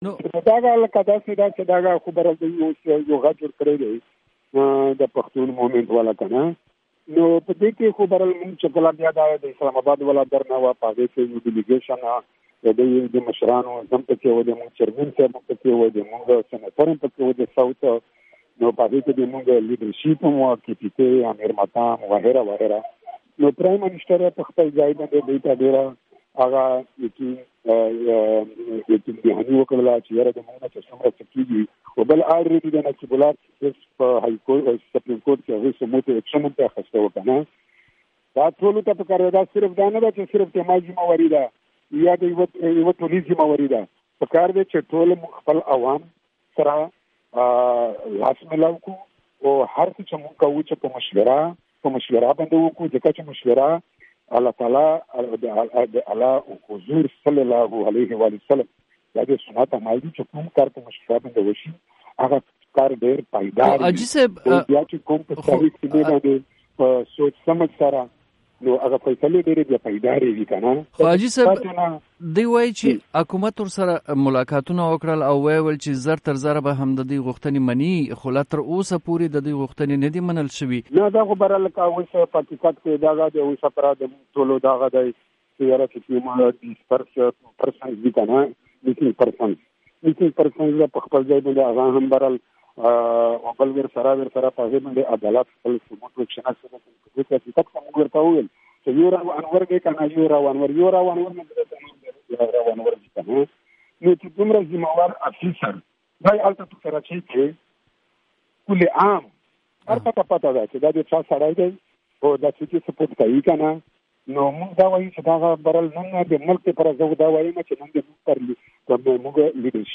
د حاجي غالب مرکه